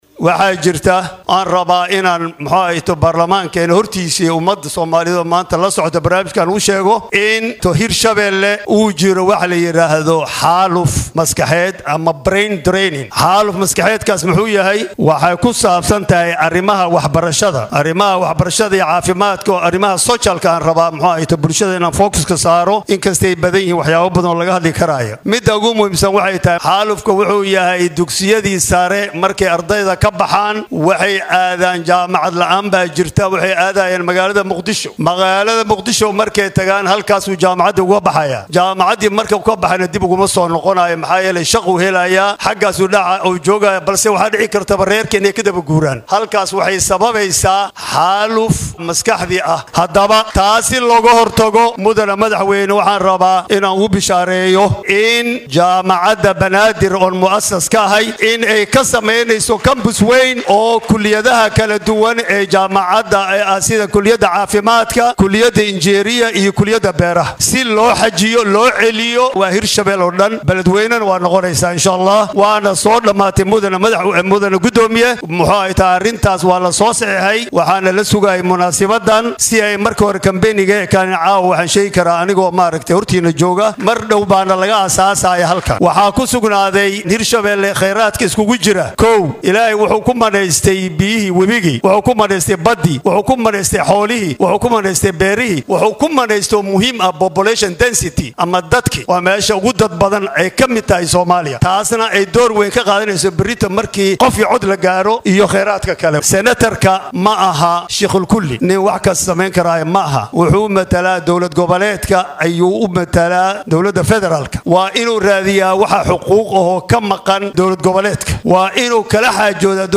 DHAGEYSO: Prof Dufle oo ka hadlay guushiisa mar kale ee xubinimada aqalka Sare